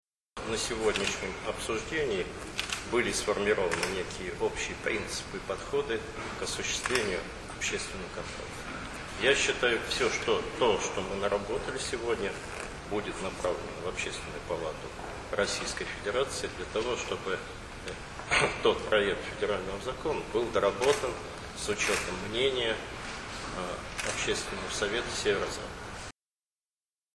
Заседание Общественного совета Северо-Запада состоялось в Вологде (ФОТО, АУДИО)
Владимир Булавин рассказал об общественном контроле